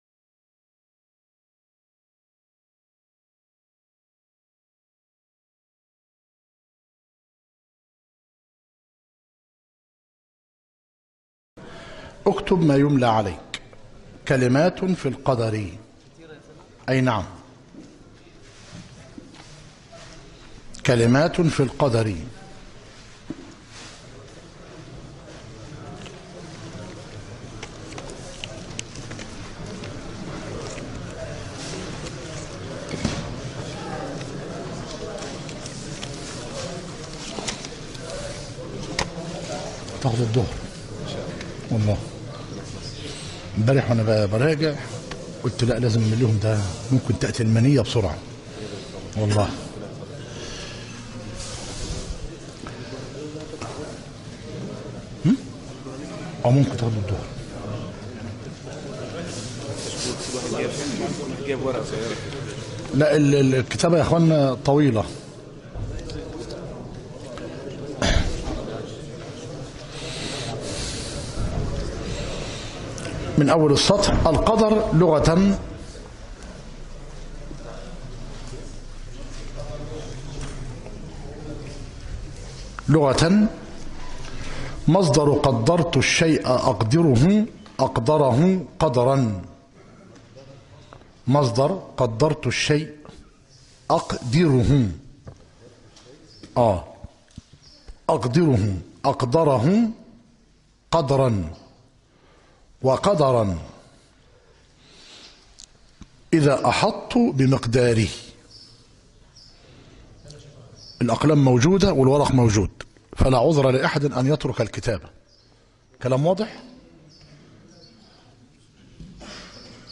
مسجد التوحيد - ميت الرخا - زفتى - غربية